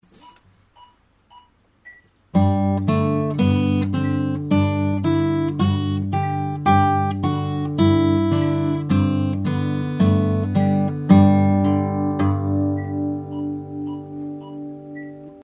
Nu spelar vi en G dur skala i fjärdedelar över basgången.
Fingerstyle 3.mp3